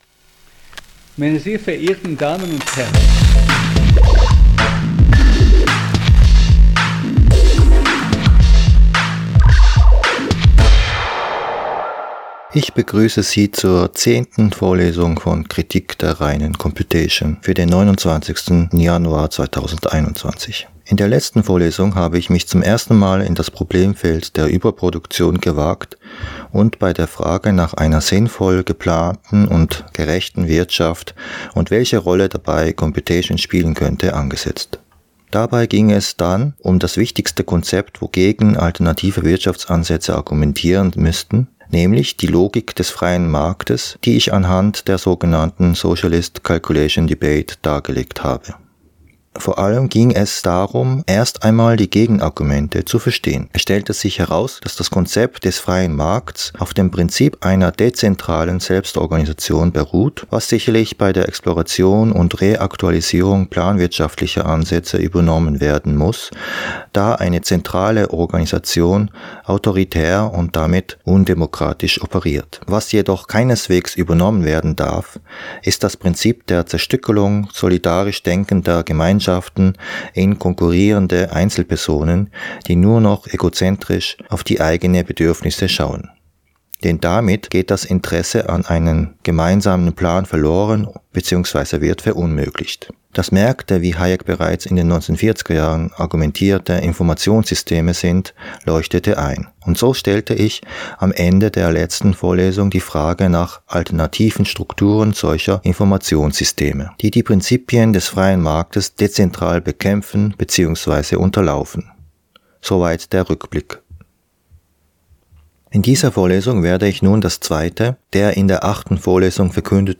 VL 010, Kritik der reinen Komputation ~ Podcastvorlesungen Podcast